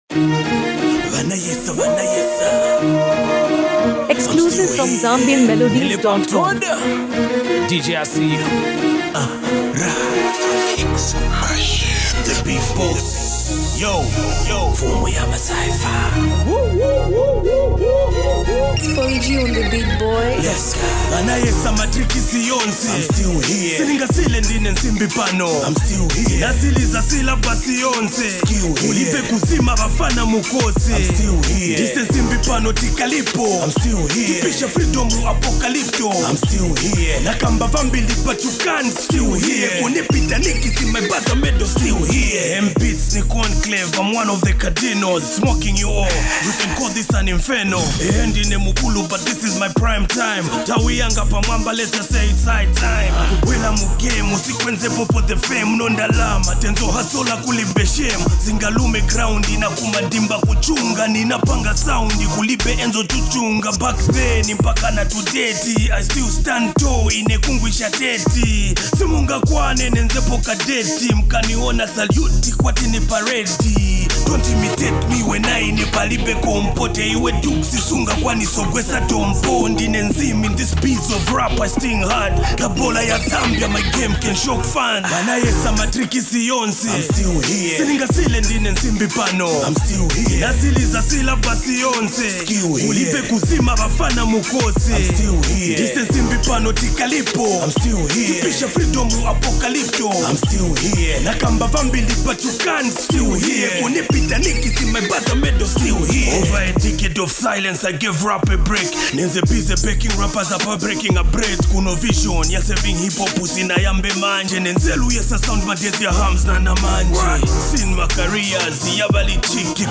Latest Zambian Rap
Genre: Hip Hop